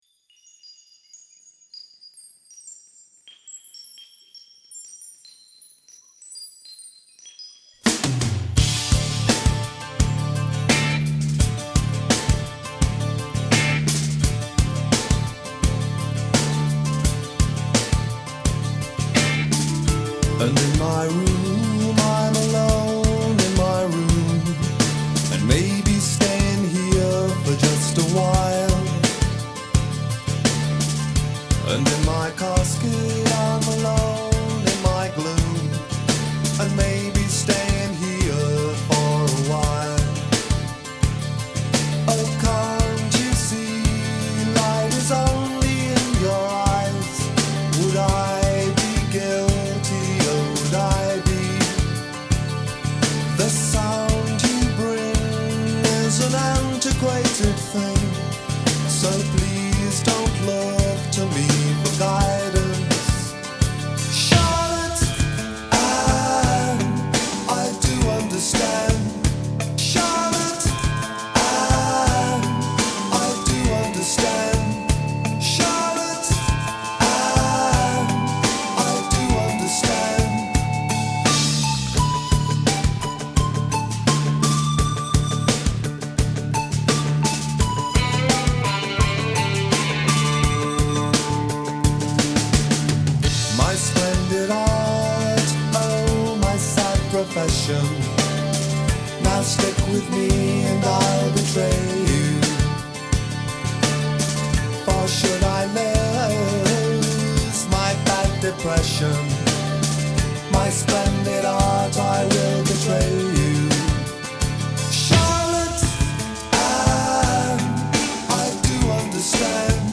British pop single